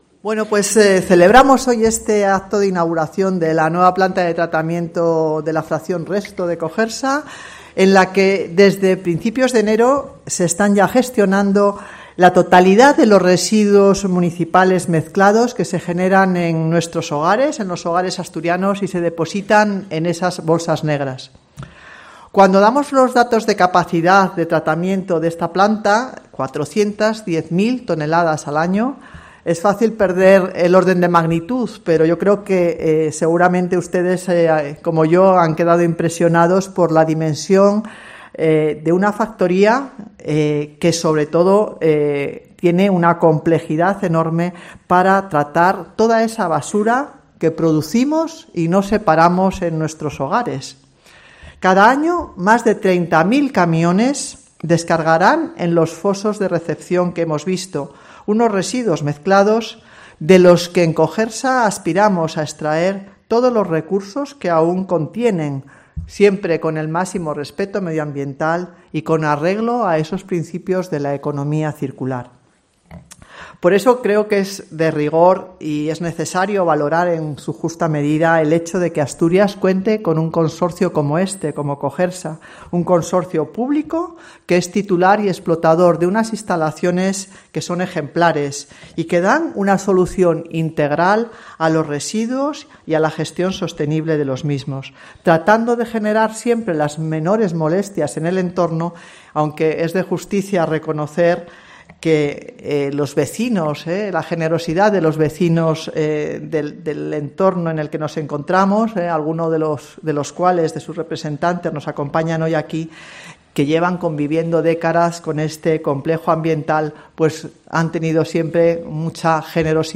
La presidenta de COGERSA, Nieves Roqueñí, presenta las características de la nueva 'Plantona'